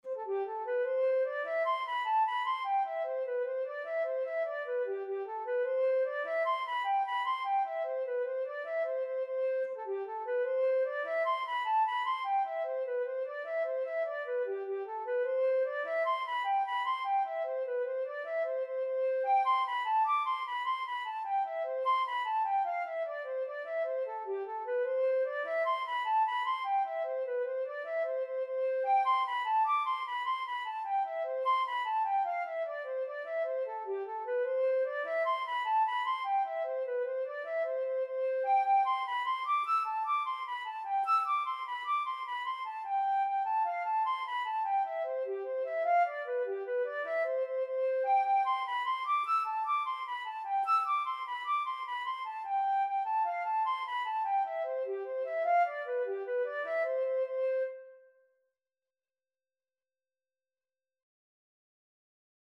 Free Sheet music for Flute
C major (Sounding Pitch) (View more C major Music for Flute )
6/8 (View more 6/8 Music)
G5-E7
Flute  (View more Easy Flute Music)
Traditional (View more Traditional Flute Music)